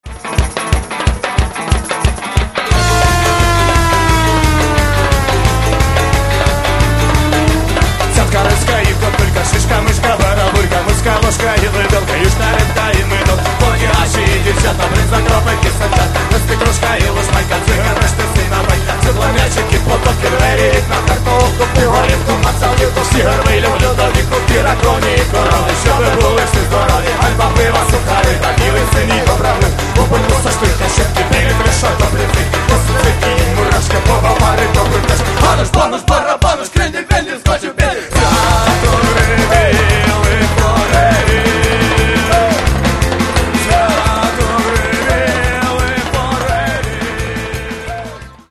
Еще немного громче и – до свидания!